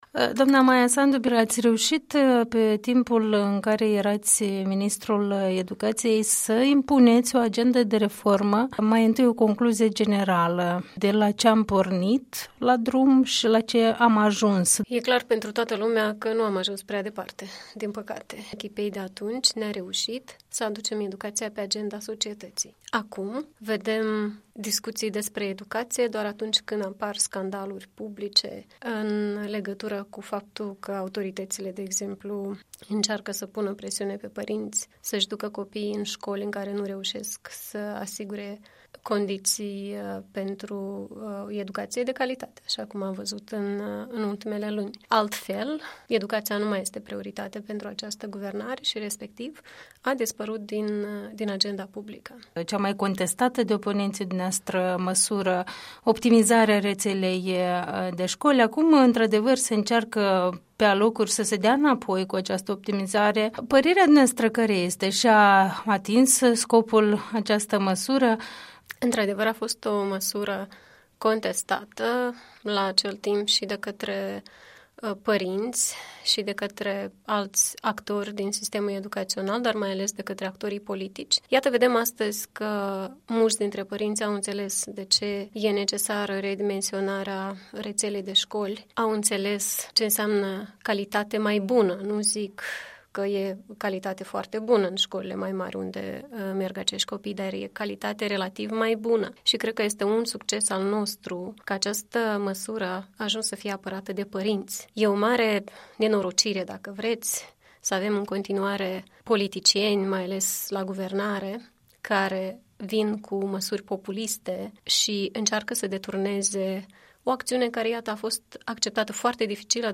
Interviu cu Maia Sandu